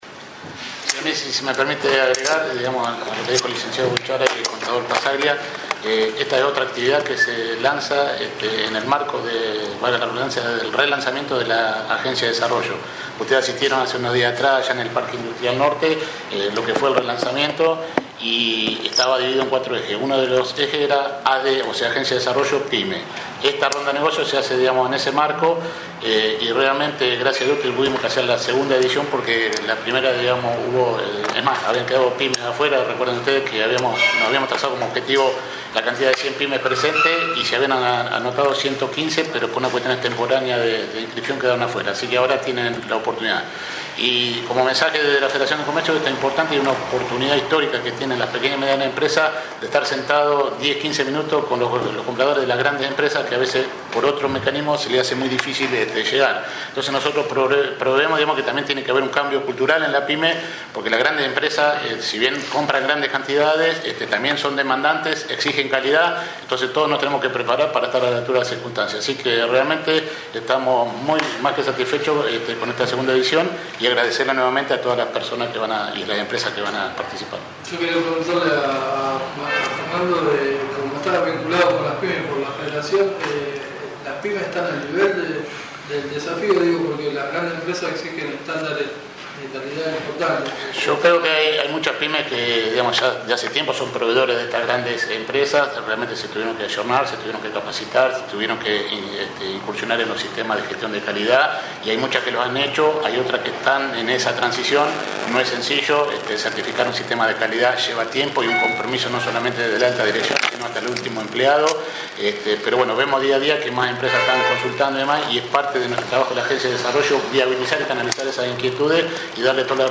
Se realizó la reunión como estaba previsto en las instalaciones de la Federación de Comercio e Industria de San Nicolás.
Muy pragmático  para realizar la charla y con mucho entusiasmo explicó ante un concurrido auditorio las nuevas ventajas que trae tomar algunos de los crédito que presento en un abanico de posibilidades, pero especialmente ofreció tener en cuenta a las pymes todo lo que traían especialmente.
Audio: Presentación y charla